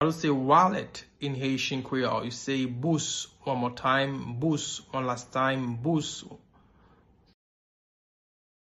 Pronunciation:
Listen to and watch “Bous” audio pronunciation in Haitian Creole by a native Haitian  in the video below:
32.How-to-say-Wallet-in-Haitian-Creole-–-Bous-pronunciation.mp3